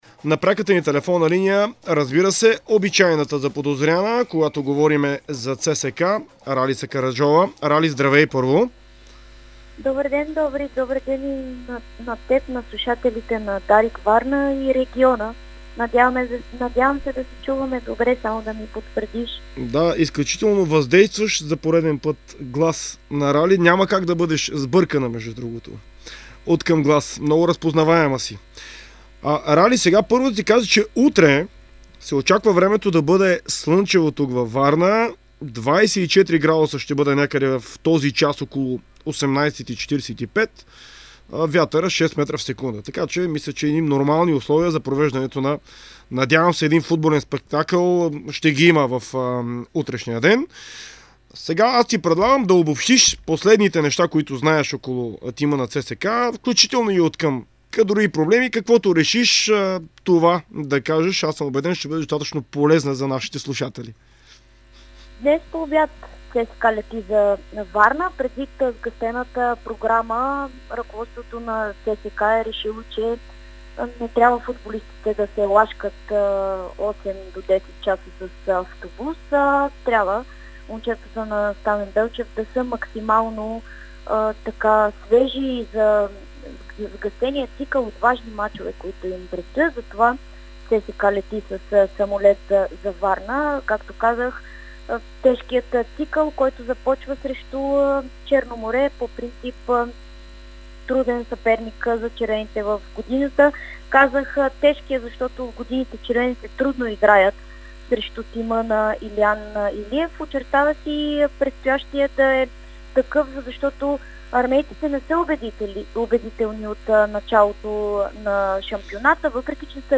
В спортното предаване на “Дарик Варна” двамата коментираха моментното състояние на отборите, проблемите, които ги измъчват, схемите на игра, очакванията преди срещата, както и силните и слабите им страни.